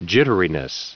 Prononciation du mot : jitteriness
jitteriness.wav